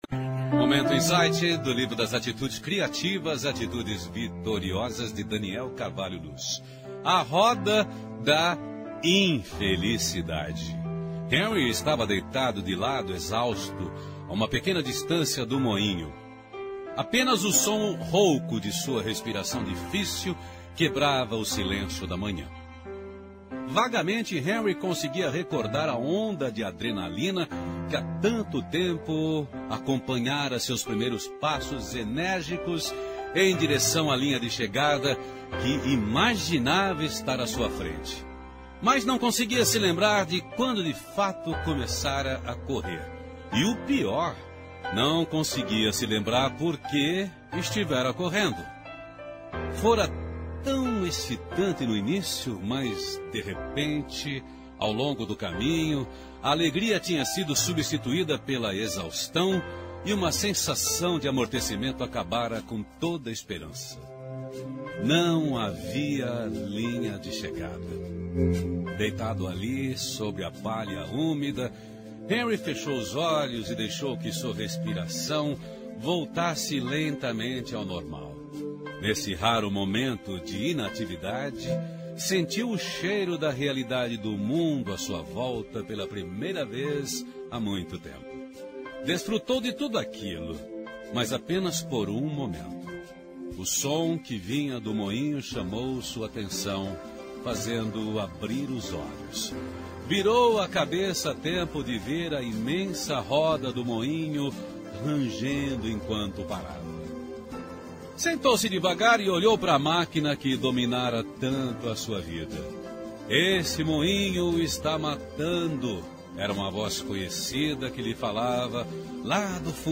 Texto adaptado do livro Insight de Daniel C. Luz interpretado por Irineu Toledo.